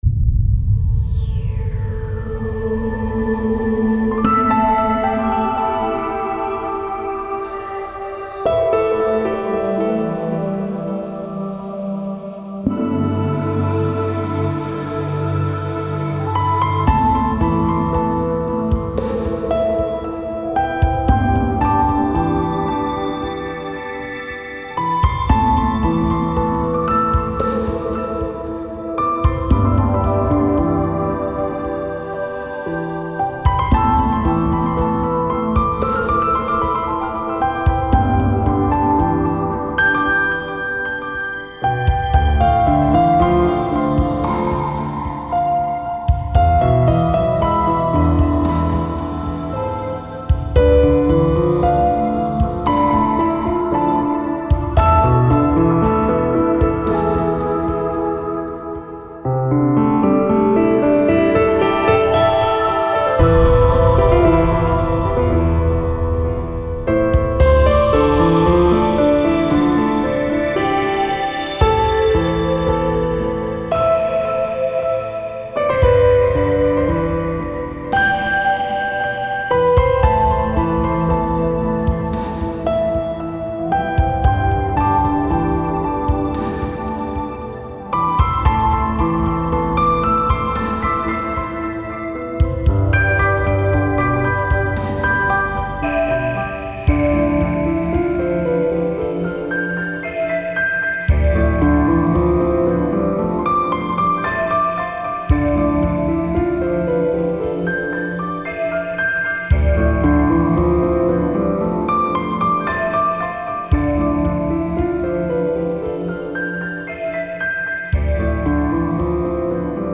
アンビエントなシンセサウンドと、物悲しく優しいピアノのメロディーがメインの幻想的な楽曲です。